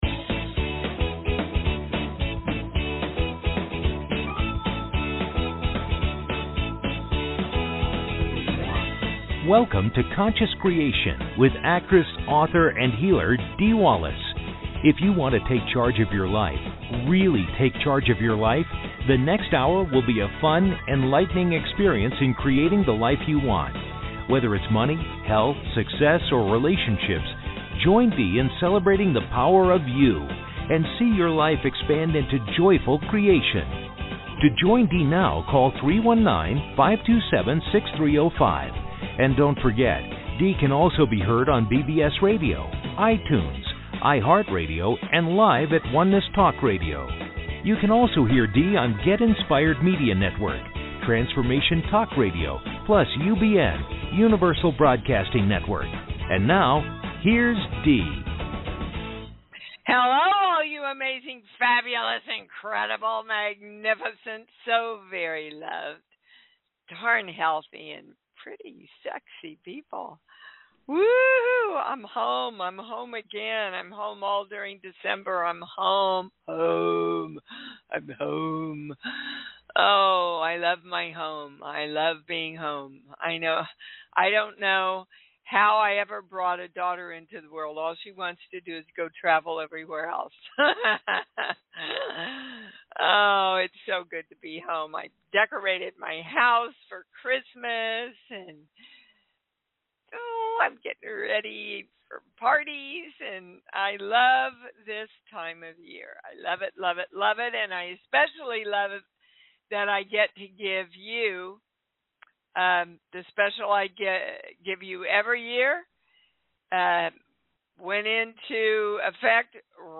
Conscious Creation Talk Show